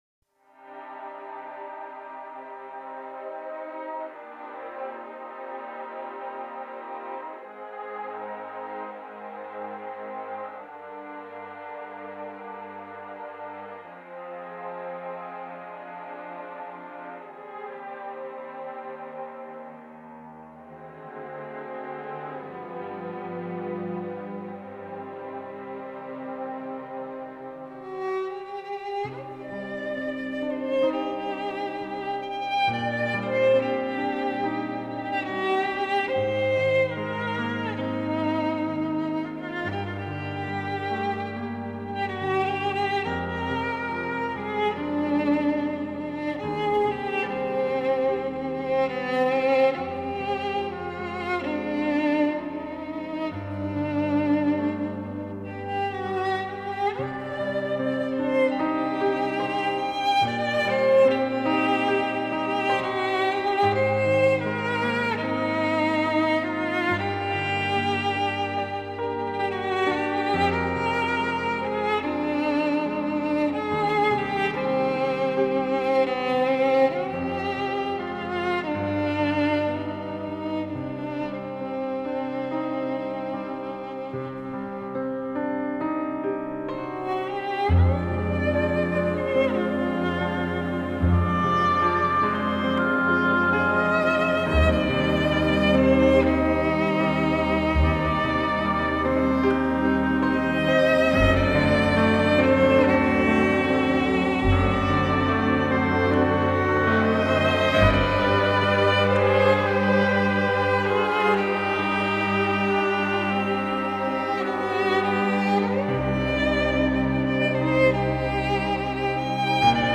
Celtic Mix